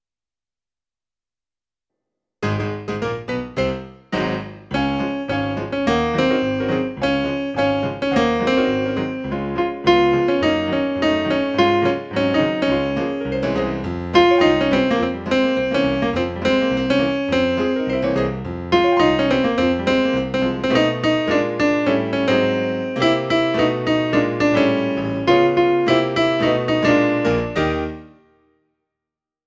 TENOR: